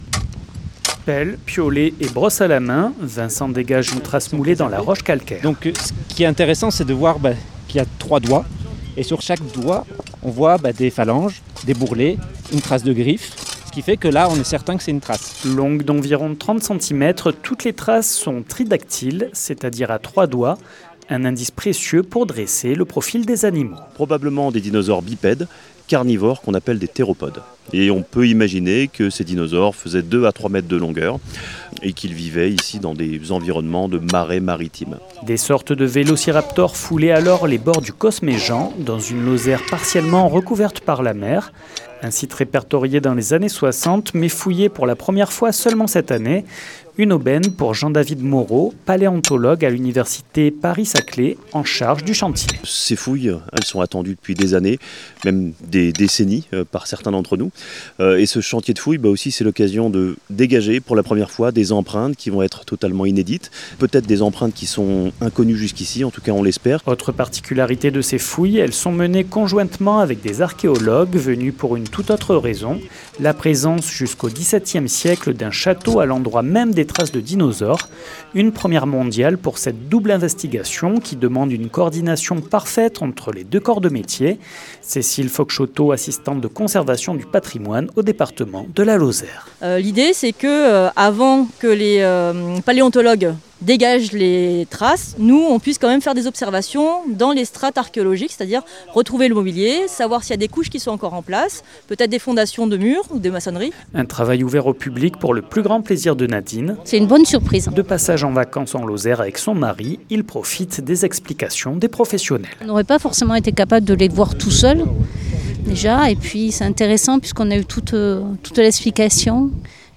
Cet été, dans le village, une dizaine de paléontologues, d’archéologues et d’amateurs ont mené des fouilles sur le site du Castellas. Des recherches historiques et une première mondiale. 48FM s’est rendu sur place !
Reportage